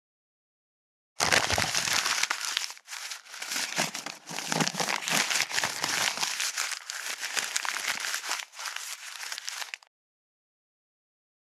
159.紙をぐしゃっと丸める【無料効果音】
効果音